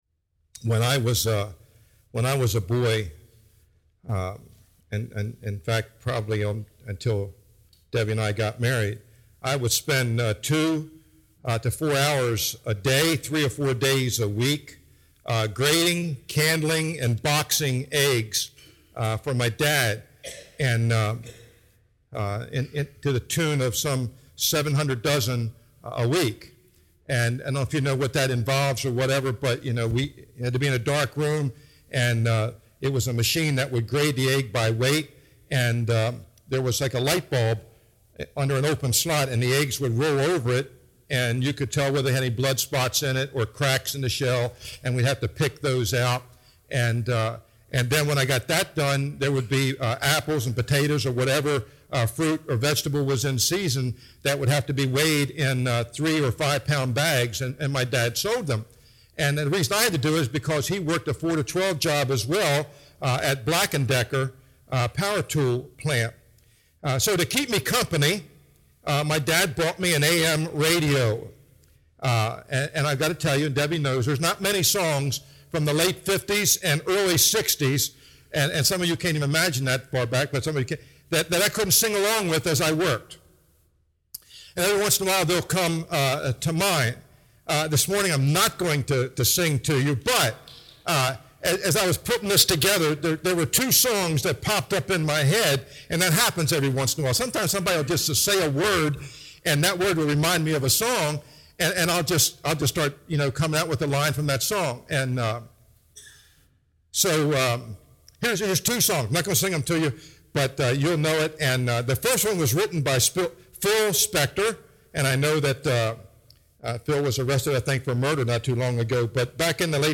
sermon
2015 NOTE* -Advanced apologies for the somewhat poor sound quality which is NOT typical of these posts, but it is audible and a very timely and profitable message. do-not-know-me audio.js 00:00